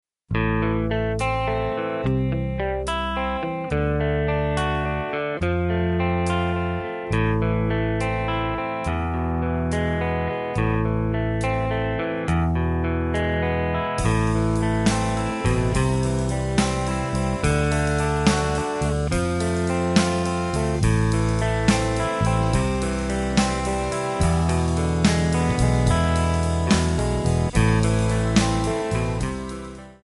Backing track files: Rock (2136)
Buy Without Backing Vocals